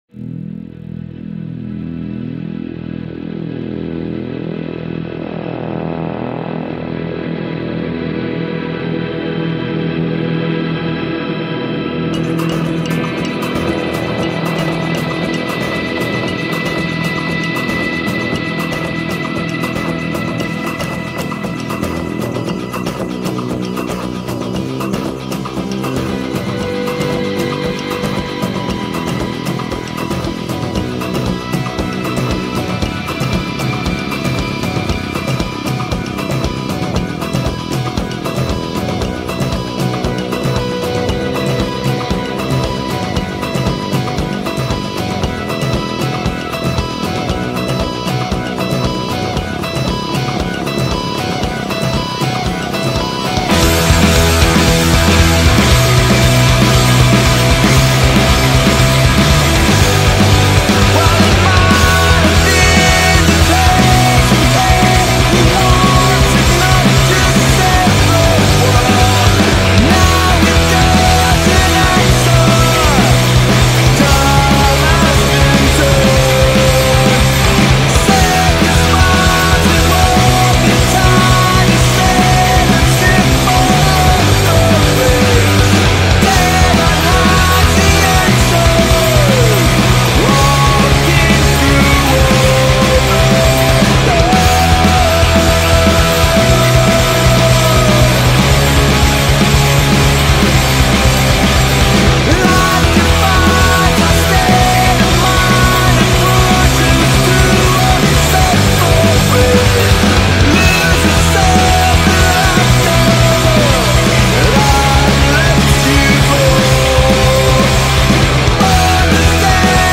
Progressive Rock, Post-Punk